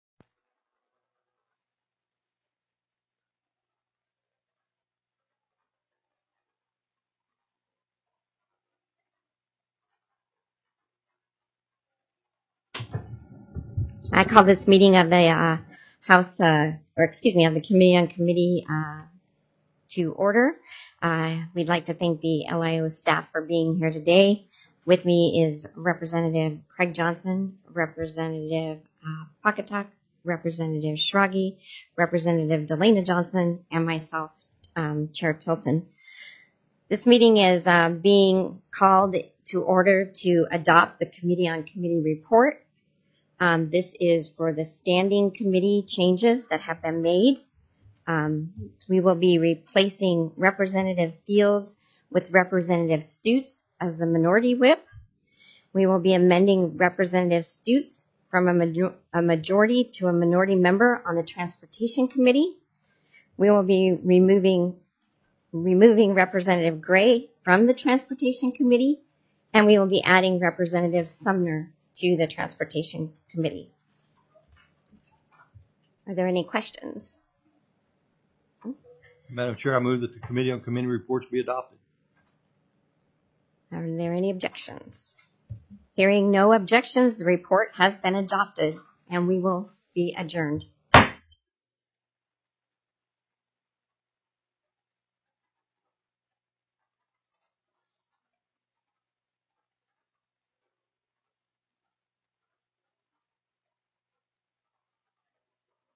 The audio recordings are captured by our records offices as the official record of the meeting and will have more accurate timestamps.
+ Standing Committee Assignments TELECONFERENCED